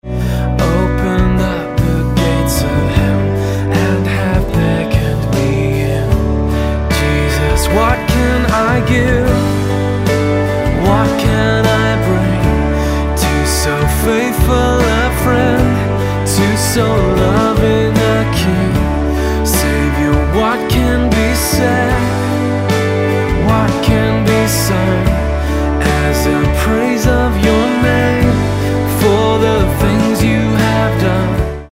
D